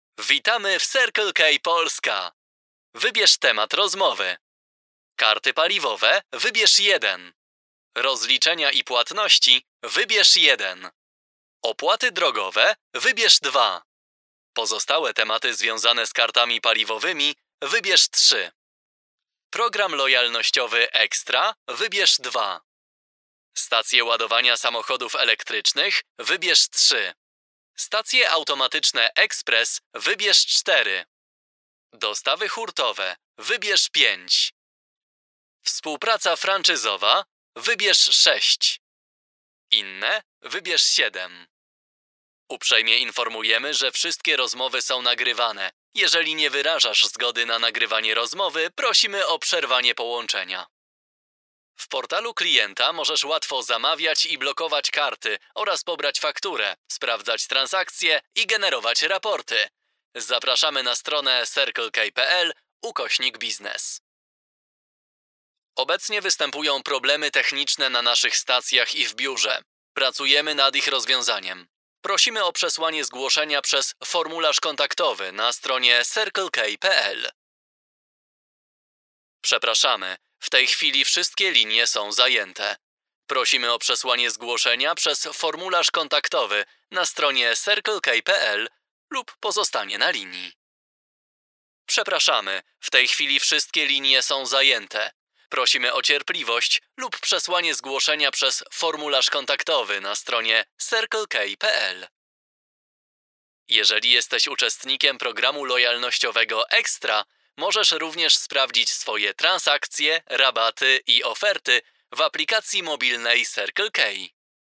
Eu gravo todos os dias no meu próprio estúdio em casa. Minha voz é percebida como quente, fresca e dinâmica.
As pessoas percebem minha voz como calorosa, suculenta, amigável, suave, otimista e inspiradora.
Eu uso microfone Neumann TLM 103; interface RME BabyFace Pro FS.
BarítonoProfundoAlto
JovemAmigáveisEsquentarDinâmicoNaturalFrescoNeutro